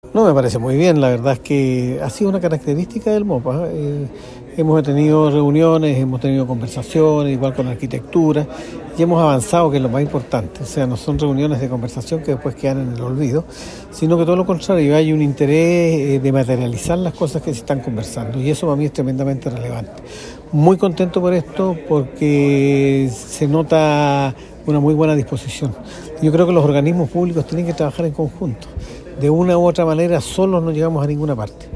Roberto-Jacob.mp3